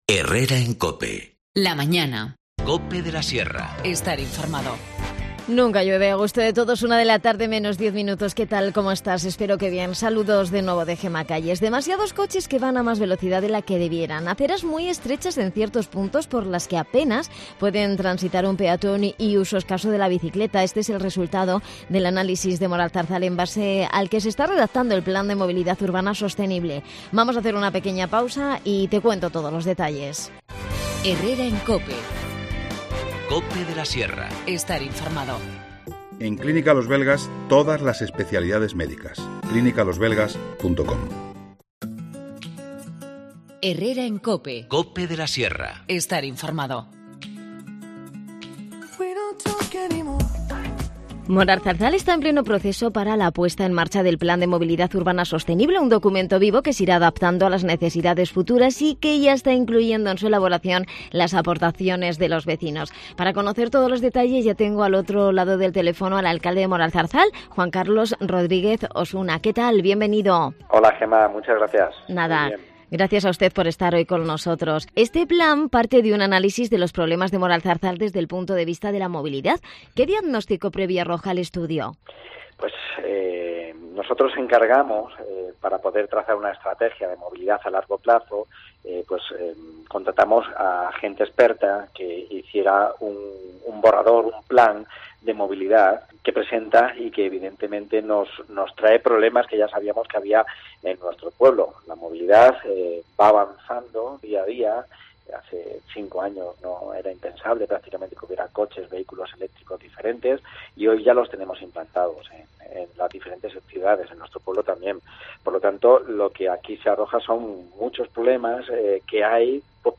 Moralzarzal está en pleno proceso para la puesta en marcha del Plan de Movilidad Urbana Sostenible. Conocemos todos los detalles de la mano de su alcalde, Juan Carlos Rodríguez Osuna.